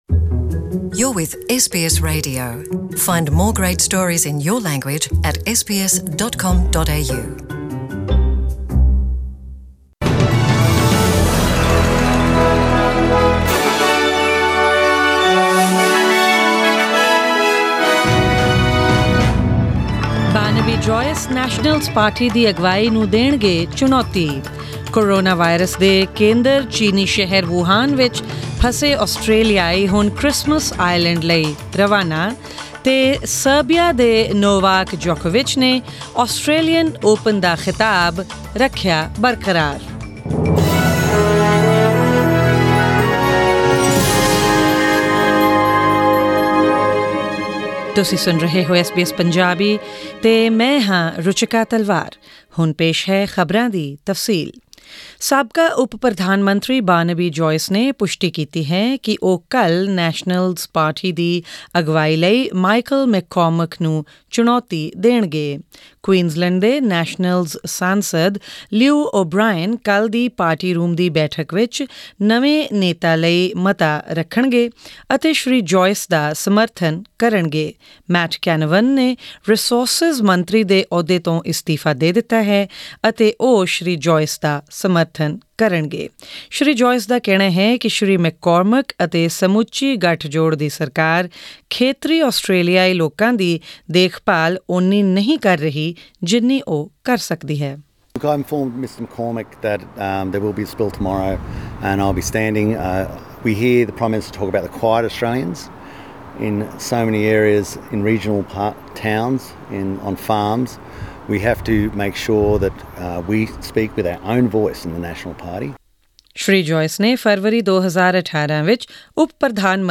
Presenting the major news stories of today with updates on sports, currency exchange rates and the weather forecast for tomorrow.